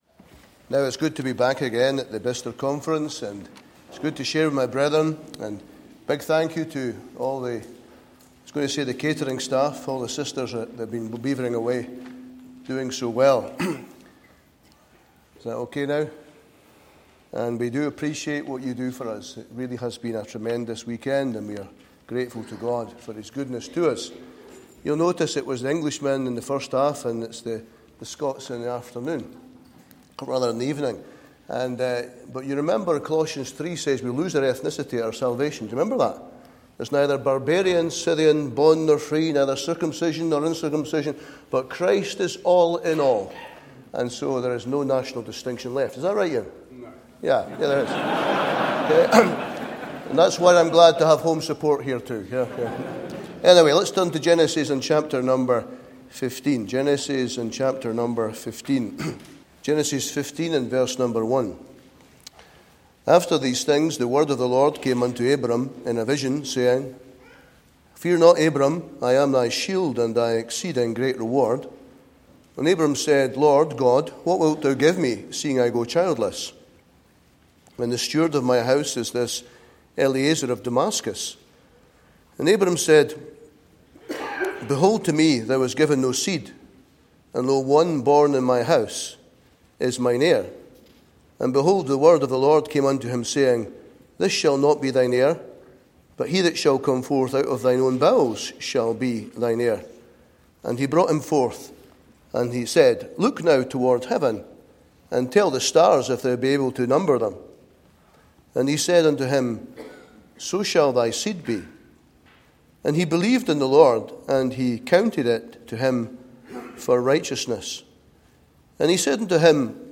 2024 Easter Conference